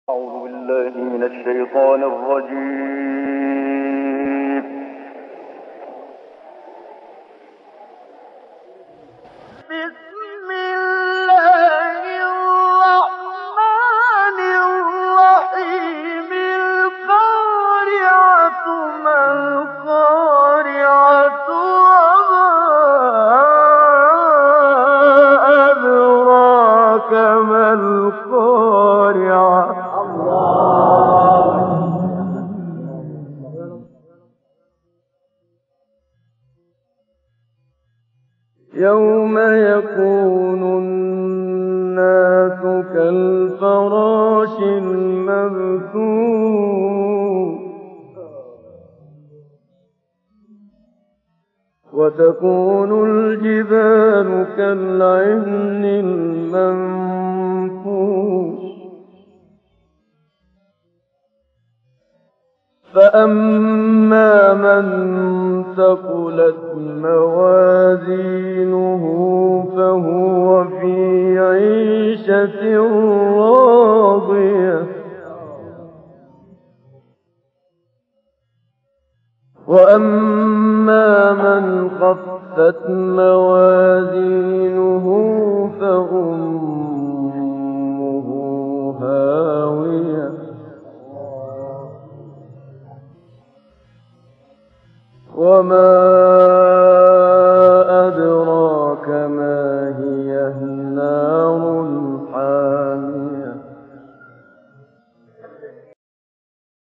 تلاوت سورۀ قارعه توسط استاد محمد صدیق منشاوی | نغمات قرآن | دانلود تلاوت قرآن